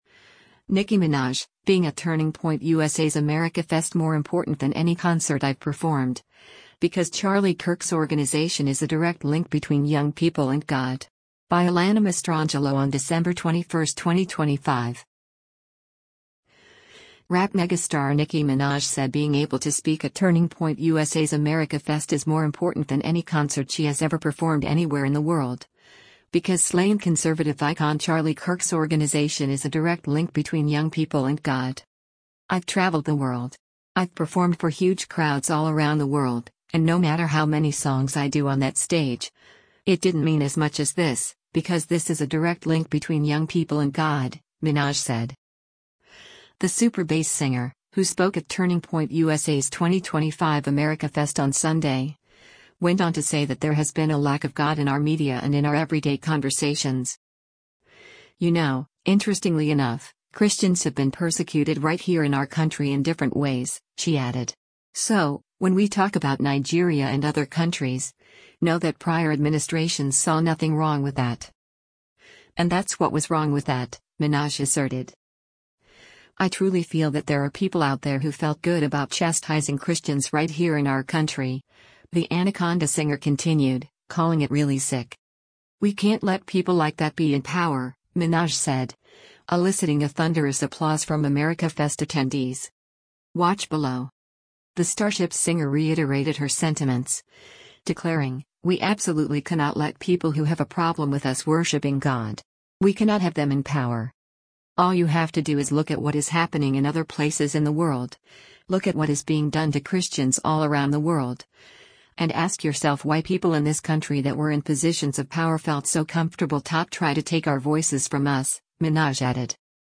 The “Super Bass” singer, who spoke at Turning Point USA’s 2025 AmericaFest on Sunday, went on to say that there has been “a lack” of God “in our media” and “in our everyday conversations.”
“We can’t let people like that be in power,” Minaj said, eliciting a thunderous applause from AmericaFest attendees.